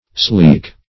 sleek - definition of sleek - synonyms, pronunciation, spelling from Free Dictionary
Sleek \Sleek\ (sl[=e]k), a. [Compar. Sleeker (sl[=e]k"[~e]r);